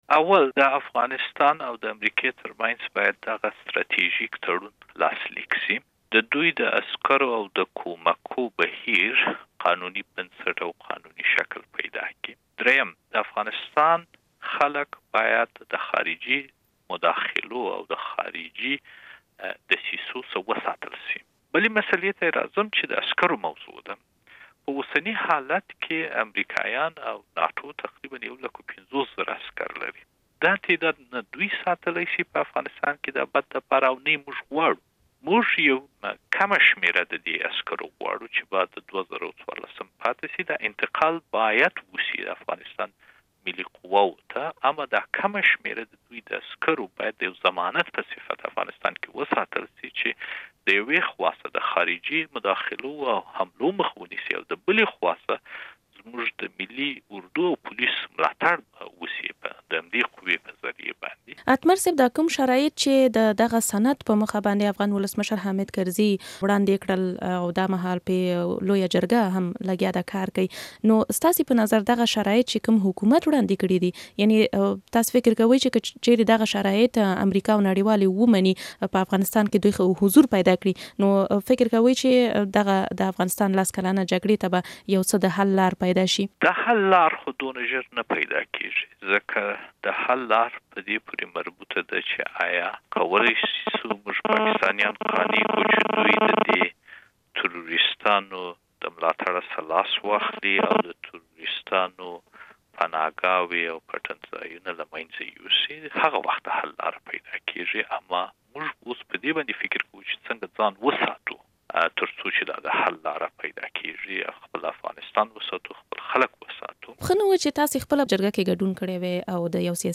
له حنيف اتمر سره مرکه